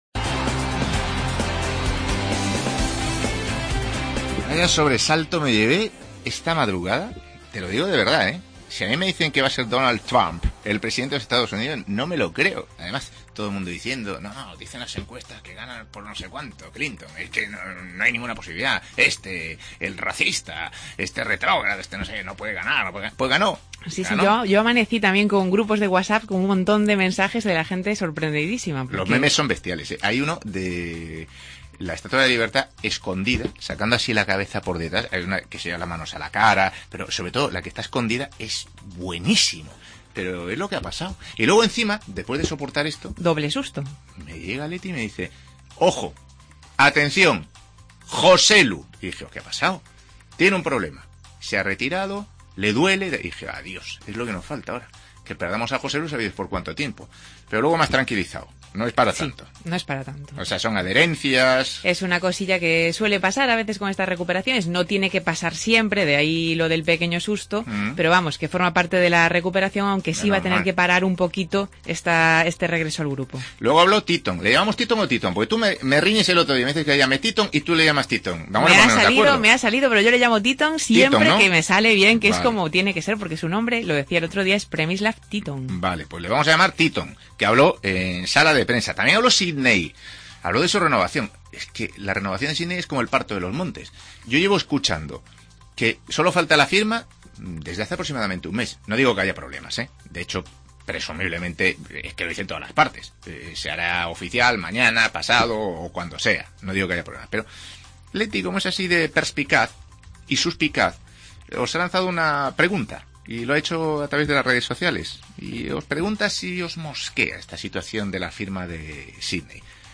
AUDIO: Susto en la recuperación de Joselu, palabras de Tyton y de Sidnei y entrevista a Lucas Pérez.